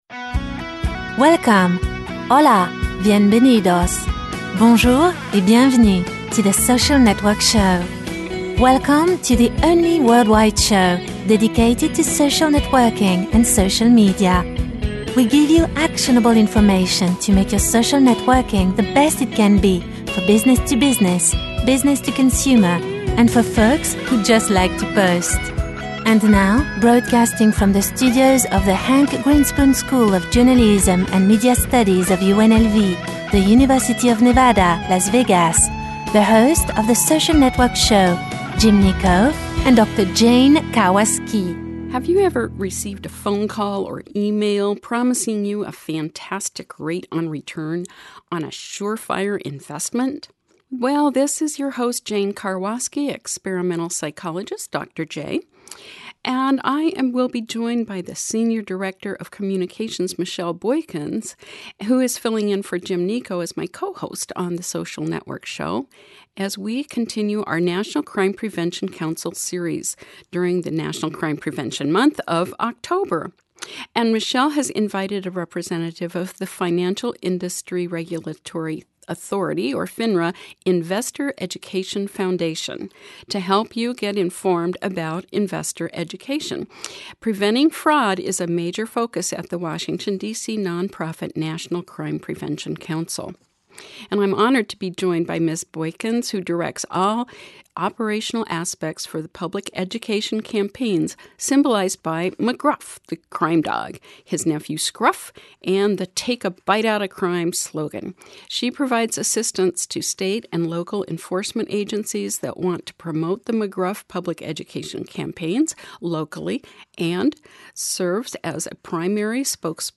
The Social Network Show welcomes the 4th episode in the series with the National Crime Prevention Council (NCPC) during Crime Prevention Month.